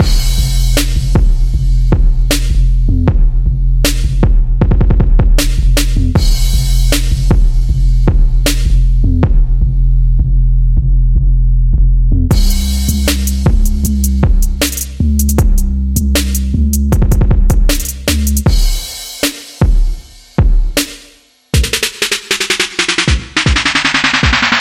描述：快乐
标签： 78 bpm Trap Loops Drum Loops 4.14 MB wav Key : Unknown
声道立体声